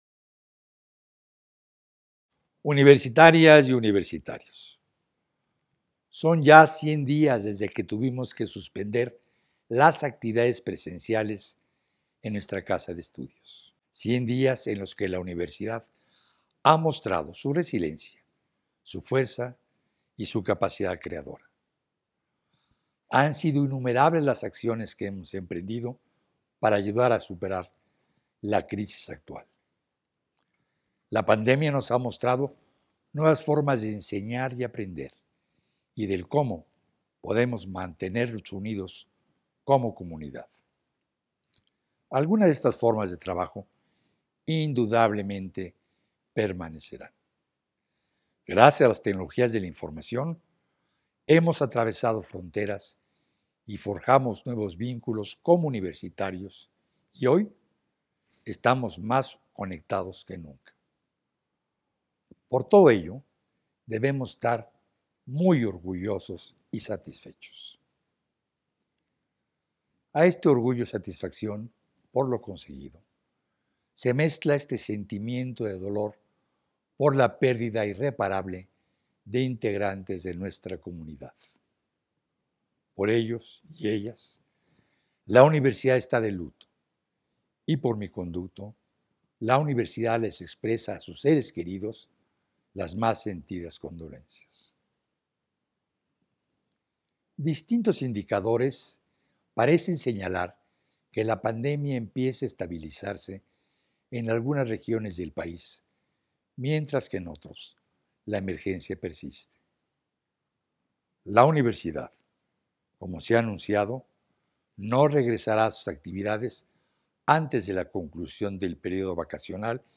Mensaje del rector Enrique Graue a la comunidad universitaria